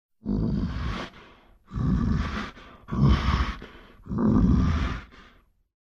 Звуки медведей
Медвежье дыхание, спокойное